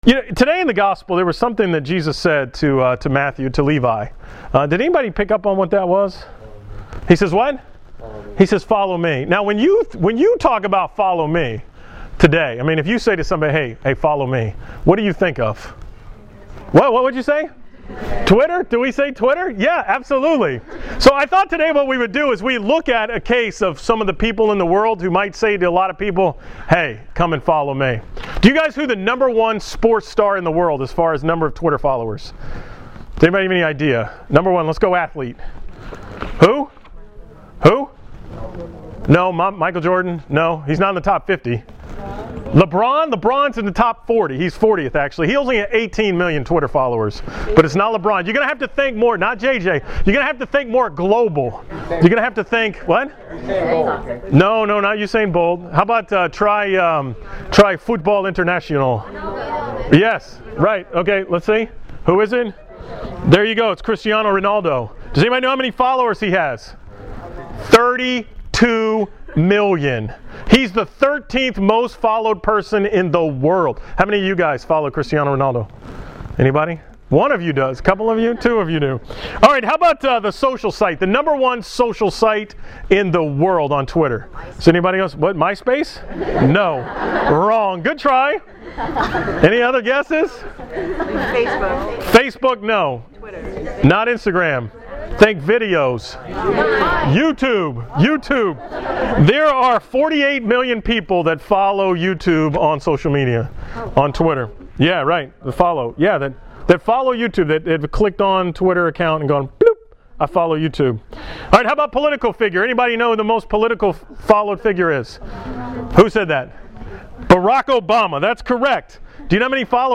From the St. Martha Confirmation retreat on January 17, 2015